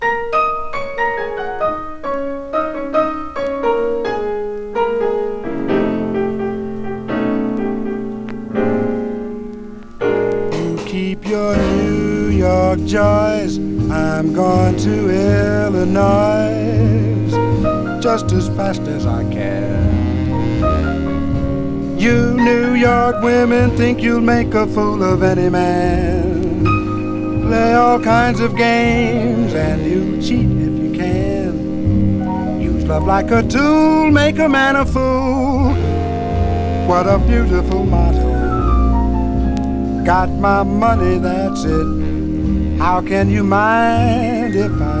ヒップに、クールに、絶妙なヴォーカルアンサンブル、軽やかに小粋にビッグ・バンド・スウィング・ジャズが楽しい良盤。